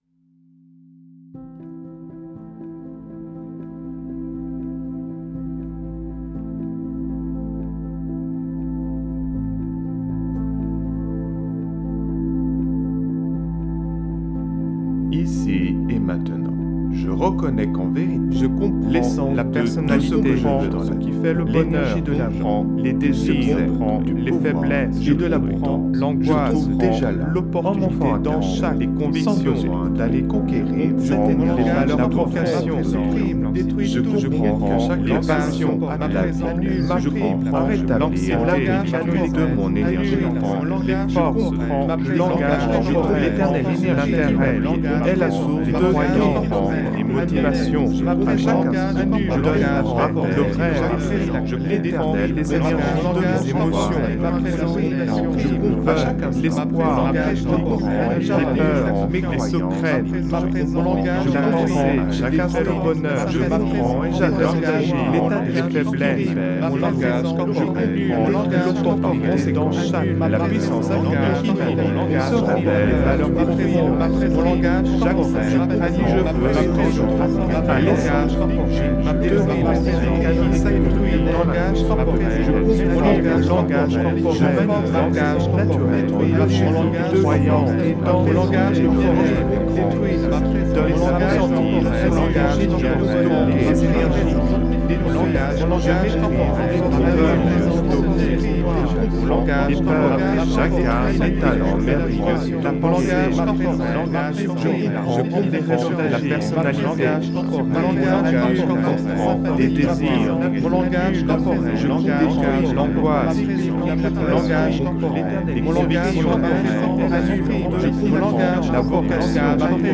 (Version INTENSE)
Alliage ingénieux de sons et fréquences curatives, très bénéfiques pour le cerveau.
Pures ondes gamma intenses 96,82 Hz de qualité supérieure. Puissant effet 360° subliminal.